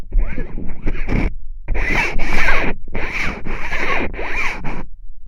천막긁는.ogg